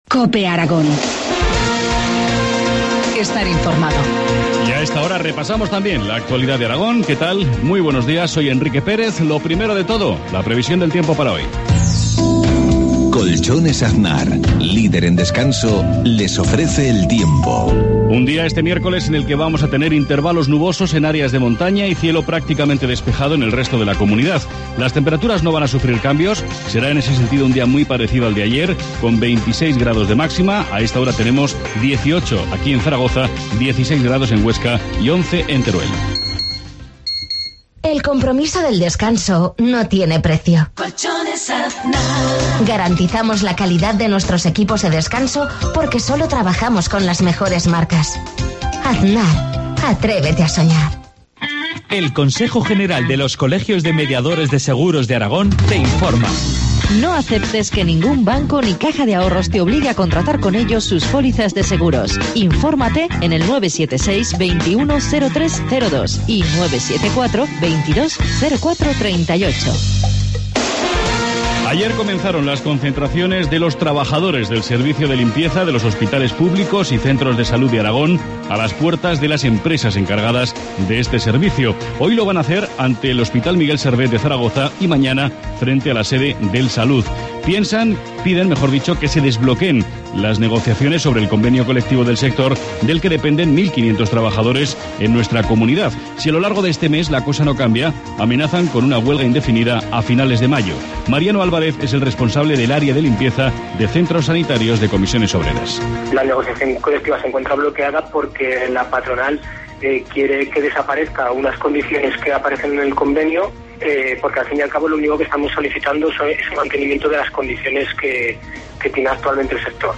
Informativo matinal, miércoles 8 de mayo, 7.53 horas